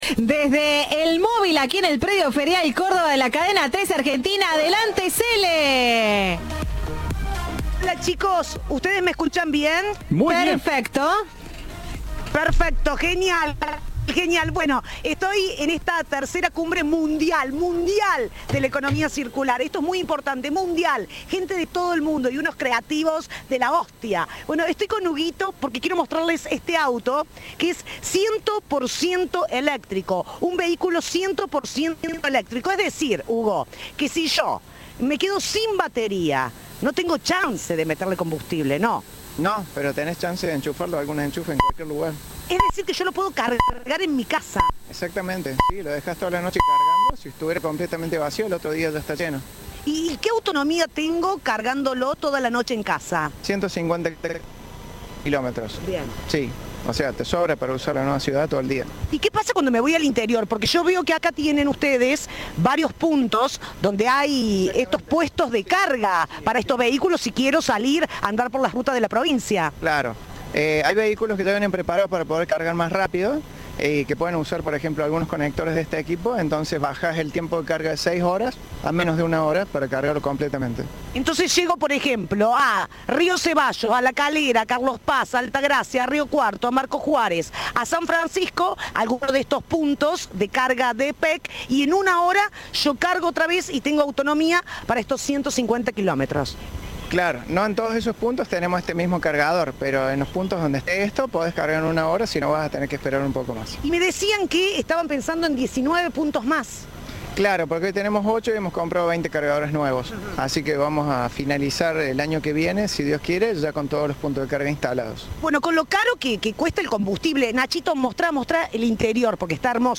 Viva la Radio en la Cumbre Mundial de Economía Circular.